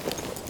Gear Rustle Redone
tac_gear_6.ogg